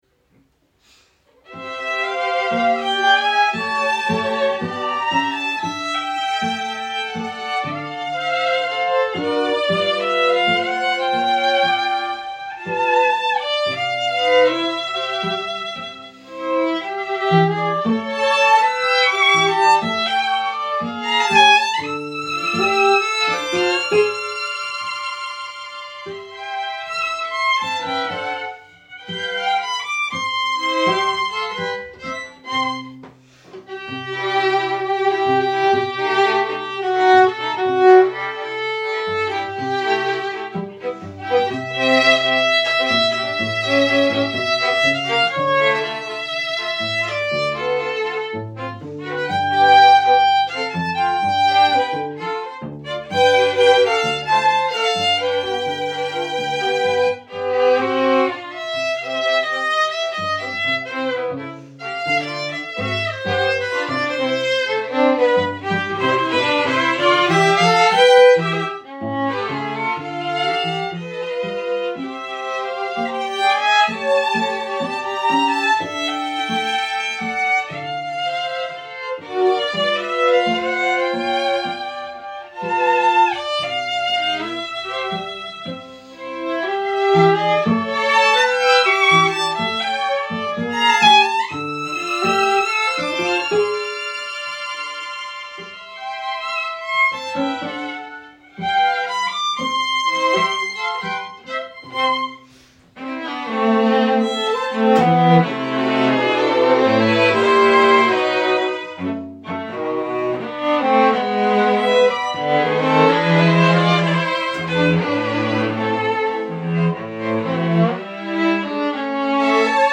A quartet of musicians from three countries now living in London.
are rehearsing in Greenwich.